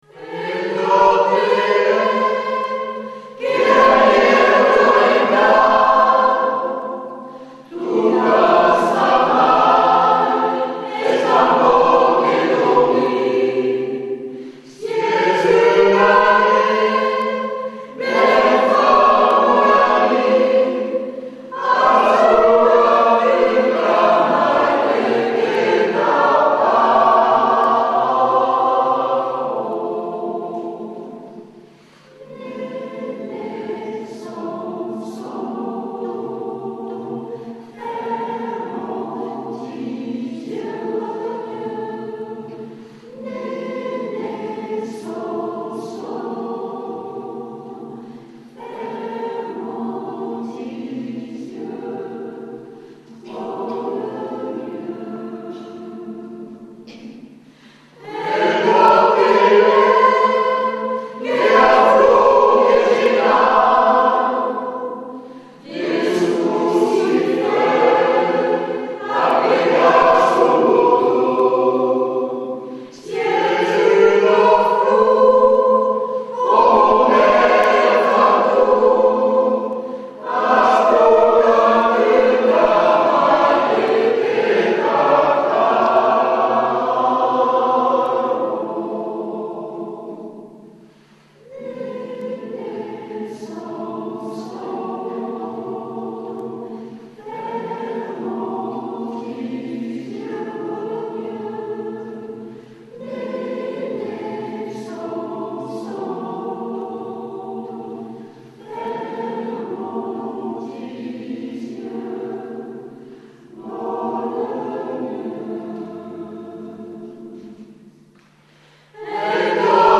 VI) Berceuses :
L-endourmitori_live_ Ensemble-Vocal-Comtadin.mp3